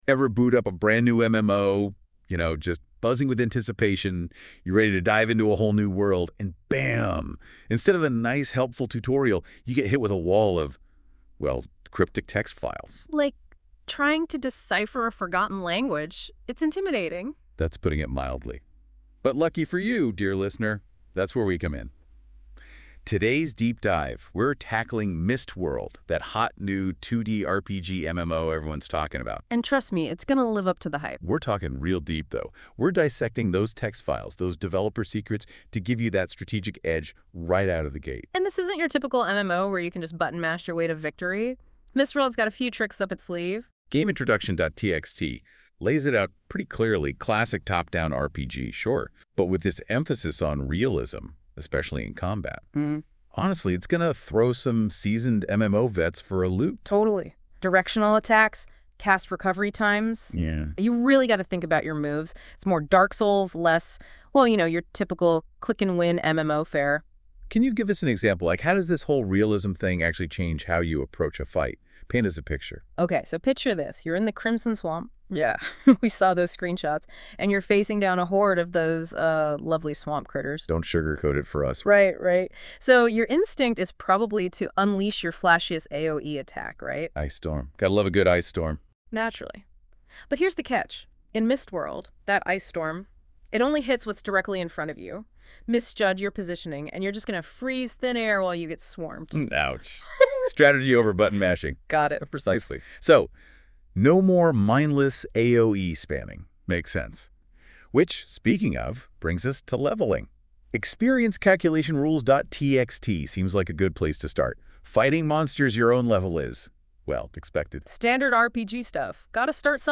I made two podcasts from the files of the MMORPG I play, Mist World, with the new Google Overview tool of NotebookLM.